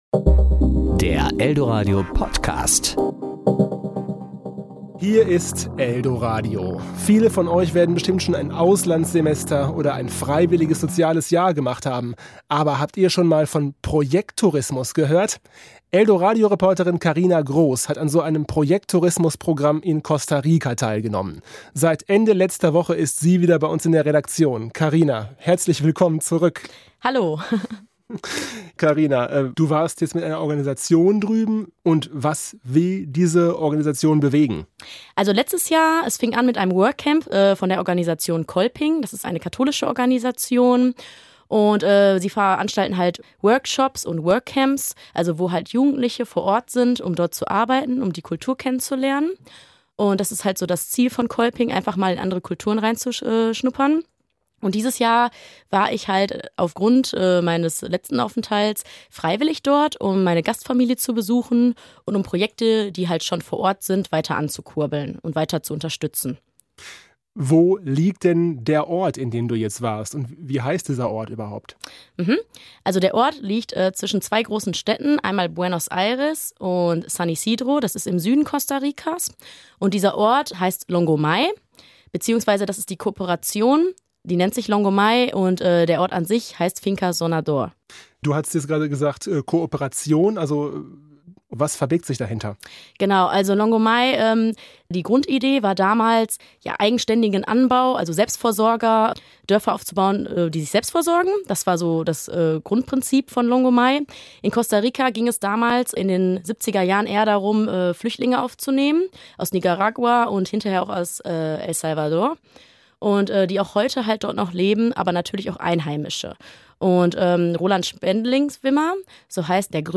Serie: Interview Sendung: Vitamin e*